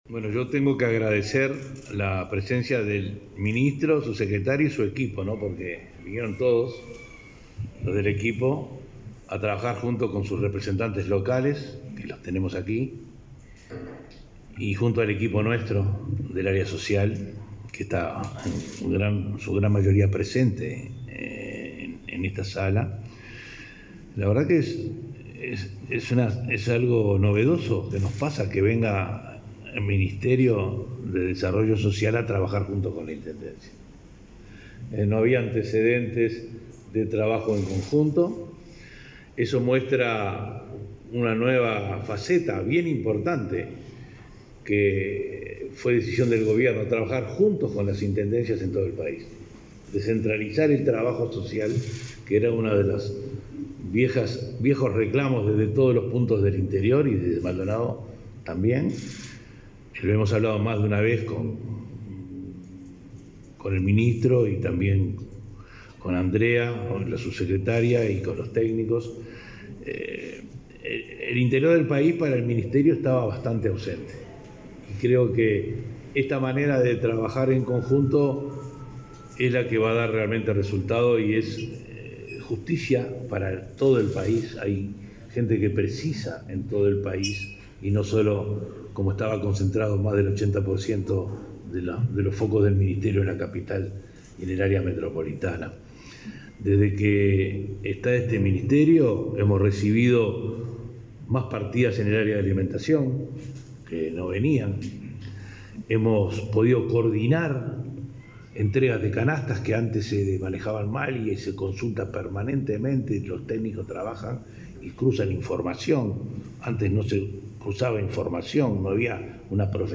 Conferencia de prensa del ministro de Desarrollo Social, Martín Lema, y del intendente de Maldonado, Enrique Antía
En ese contexto, firmaron un convenio con la Intendencia de Maldonado para beneficiar a pequeños emprendedores. Lema y Antía se expresaron en conferencia de prensa.